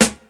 • 00s Hip-Hop Snare Drum A# Key 124.wav
Royality free snare drum tuned to the A# note. Loudest frequency: 3414Hz
00s-hip-hop-snare-drum-a-sharp-key-124-MUt.wav